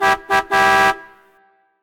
Klaxon
Fanfare au complet